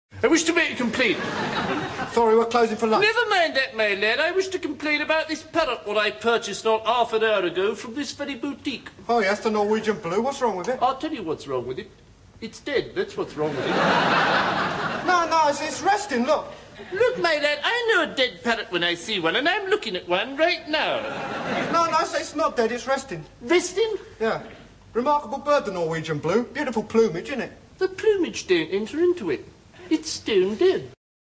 Rationale: The Dead Parrot sketch from Monty Python in 1969 (30 second extract).